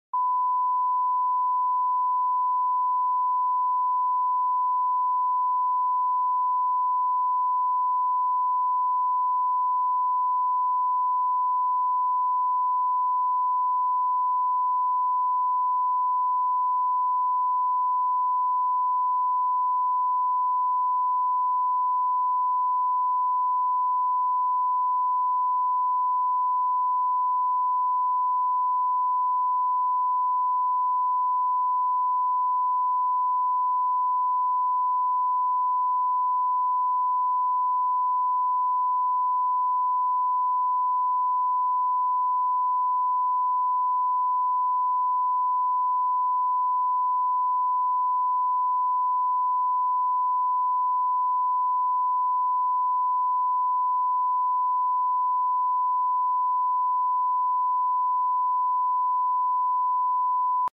NTSC Test pattern HD ToneBox sound effects free download
NTSC Test pattern HD ToneBox 1 KHz.